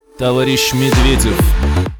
Sms сообщение
голосовые